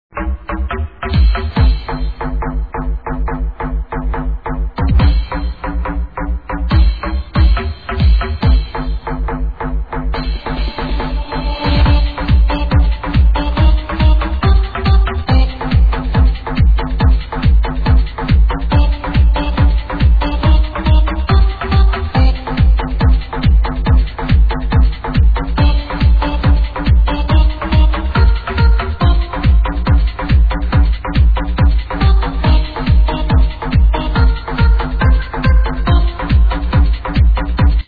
sounds like some hard house whitelabel...